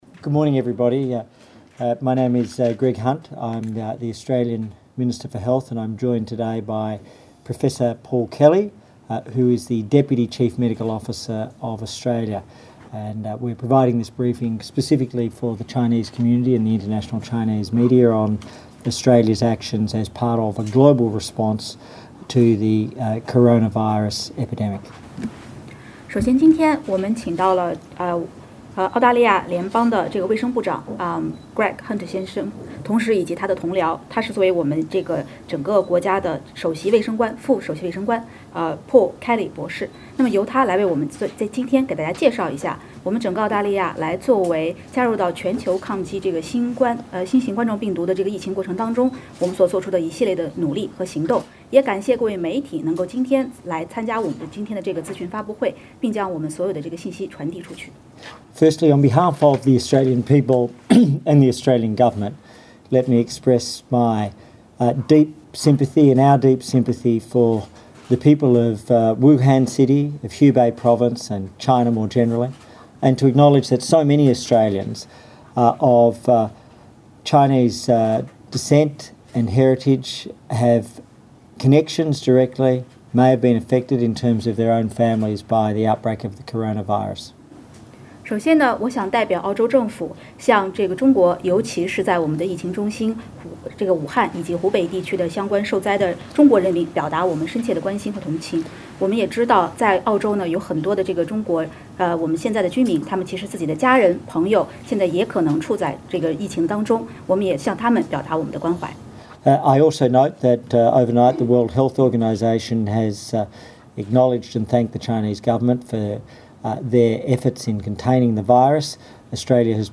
Greg Hunt MP (center), Minister for Health, in the Media briefing regarding the Coronavirus Source: SBS Mandarin